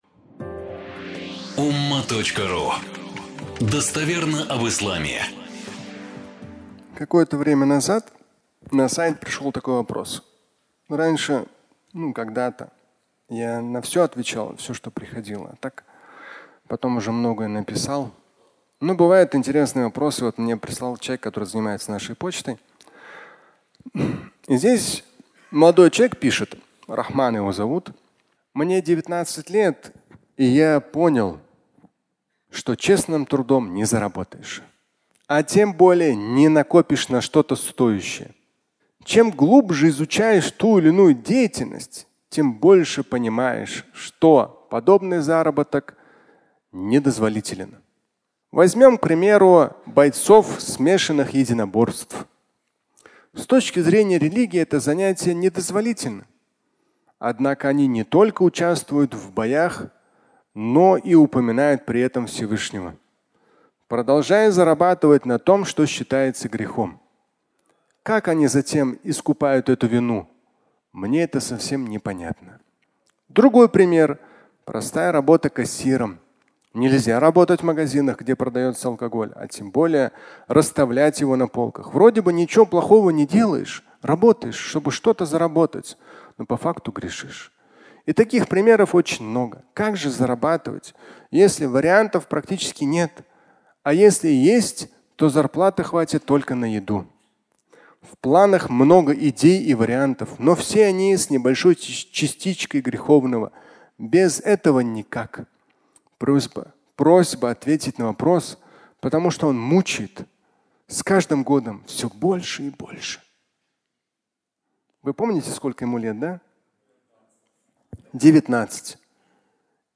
Честно не заработаешь (аудиолекция)